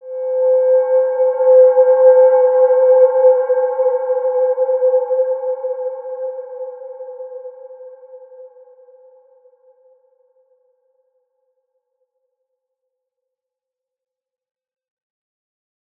Wide-Dimension-C4-p.wav